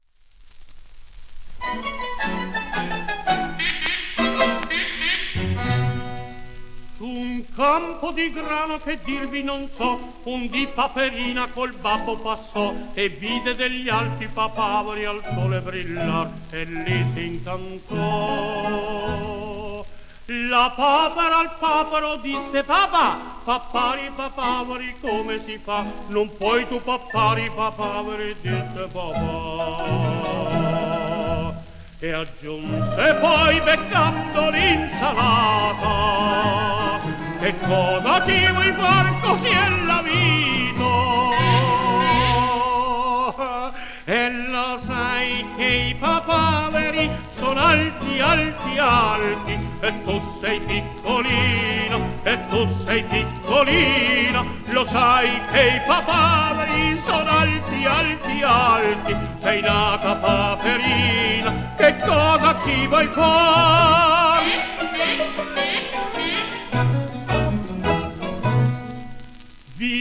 オーケストラ